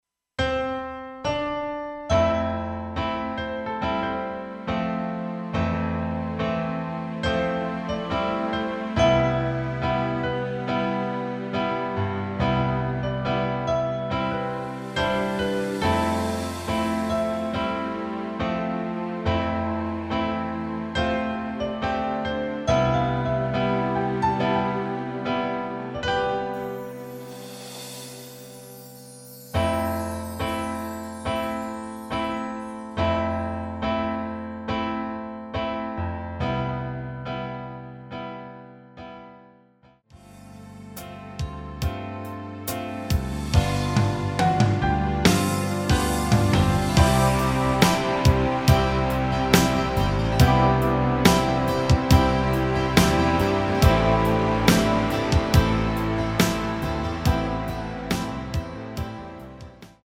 ◈ 곡명 옆 (-1)은 반음 내림, (+1)은 반음 올림 입니다.
앞부분30초, 뒷부분30초씩 편집해서 올려 드리고 있습니다.